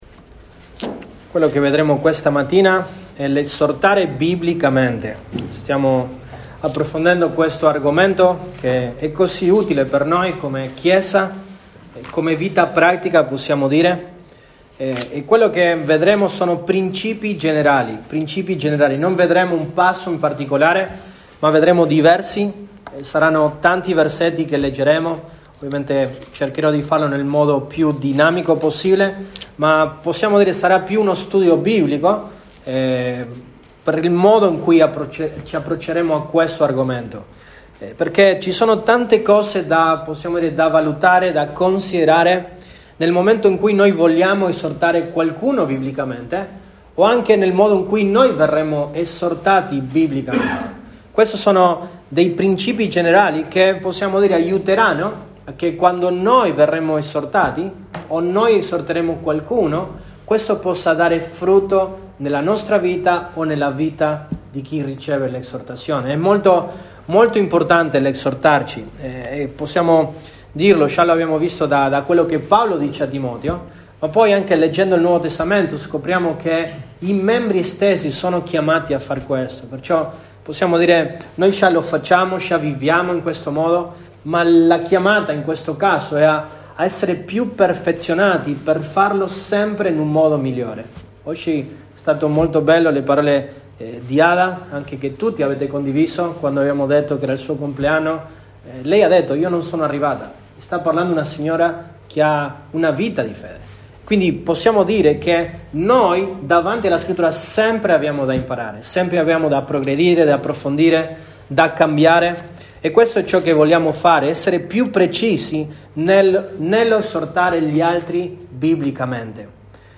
Lug 25, 2021 L’esortare biblicamente, principi generali, 1° parte MP3 Note Sermoni in questa serie L'esortare biblicamente, principi generali, 1° parte.